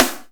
SNARE27.wav